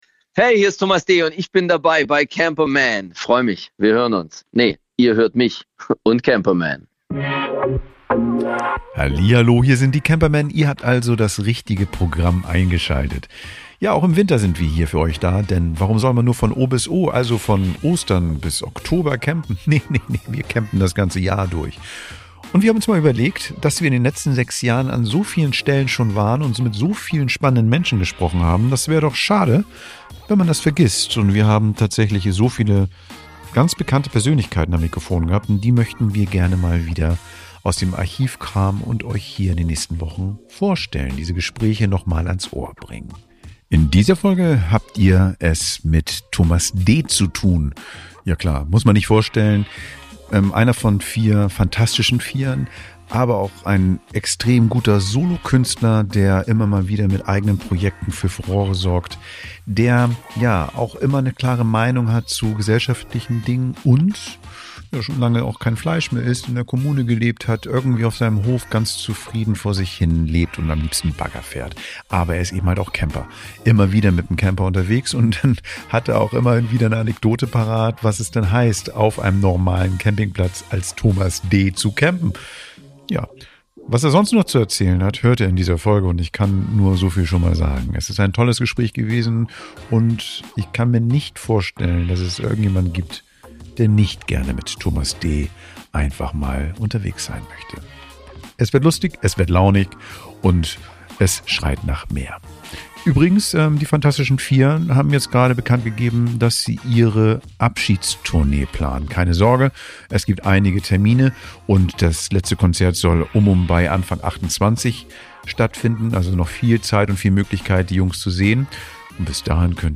In dieser Folge unserer Artist-Specials veröffentlichen wir unser Interview mit dem Musiker Thomas D.